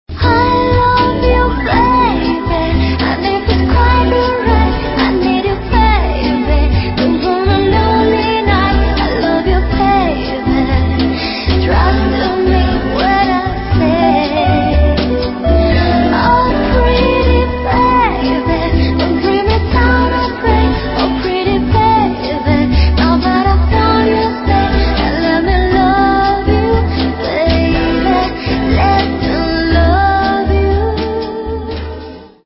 Назад в ~* Pop *~